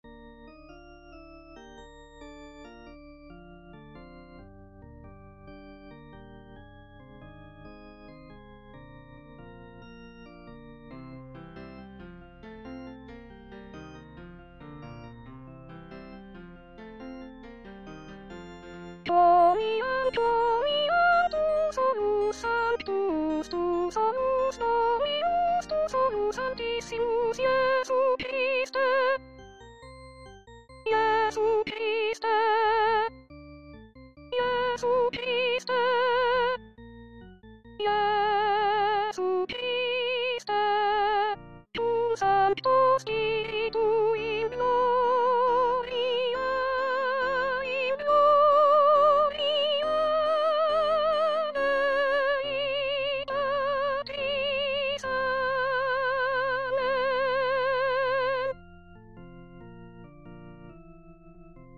Chanté:     S1